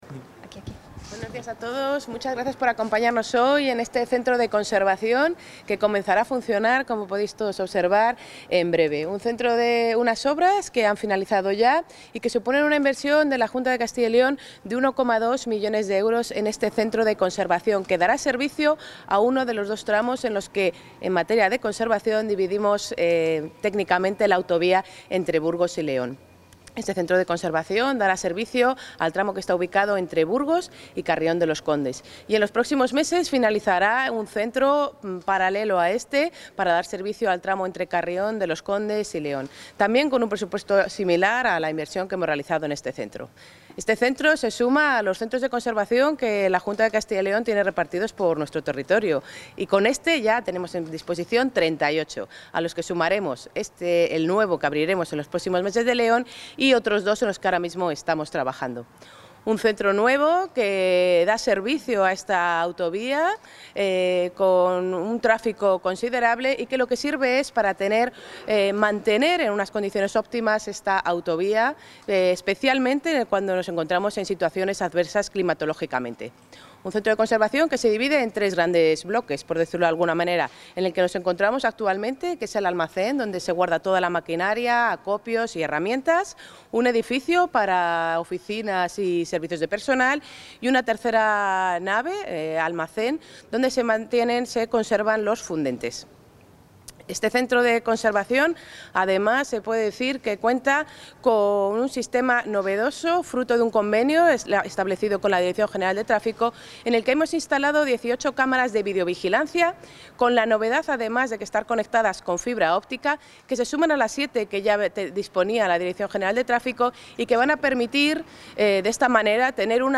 Intervención de la consejera de Movilidad y Transformación Digital.
La consejera de Movilidad y Transformación Digital, María González Corral, ha visitado hoy el nuevo Centro de Conservación de Carreteras ubicado en la Autovía del Camino de Santiago, concretamente en la localidad burgalesa de Olmillos de Sasamón, que da servicio a los 72 kilómetros del tramo comprendido entre Carrión de los Condes (Palencia) y Burgos y que ha supuesto una inversión de 1,2 millones de euros.